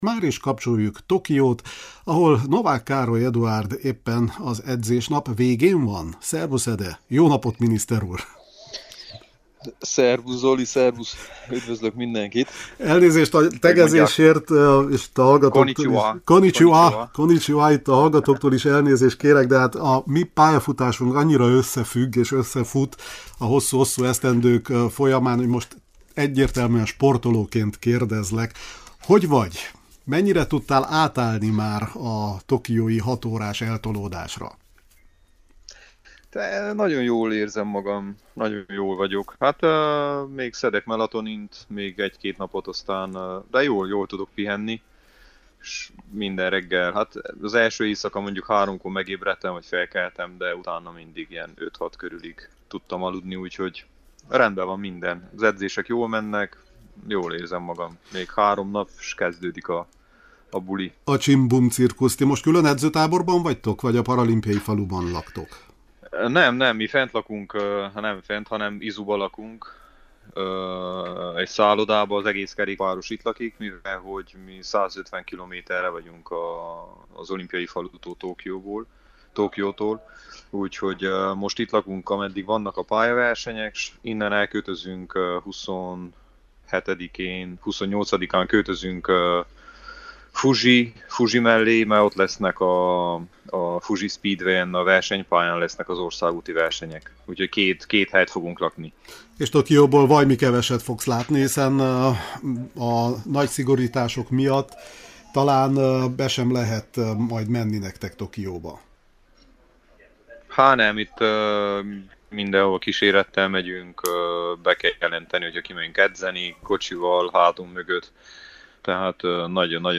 a helyszínen érte utol az internet segítségével